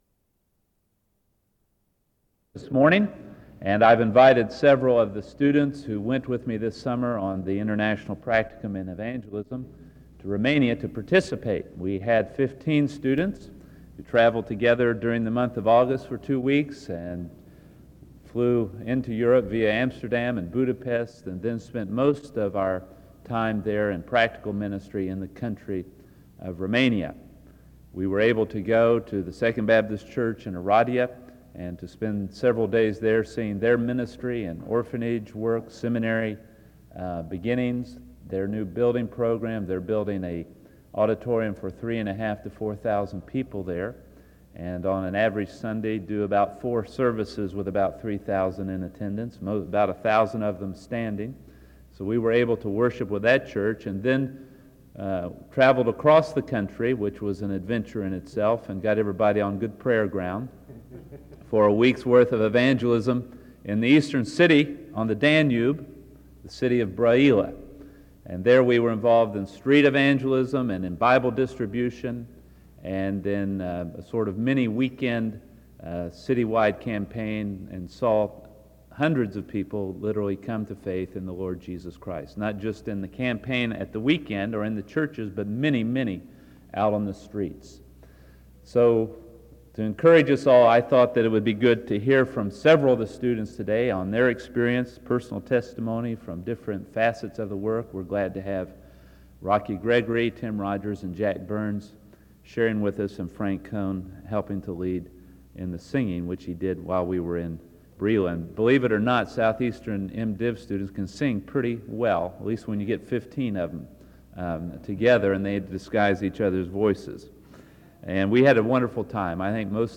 Download .mp3 Description The audio was transferred from audio cassette. This audio features multiple speakers discussing the importance of overseas missions, especially overseas missions to Romania which is where the speakers have just returned from. The first speaker speaks from 0:00-4:50 and shares a story from Romania while also reading a passage of Scripture: John 4:28-42.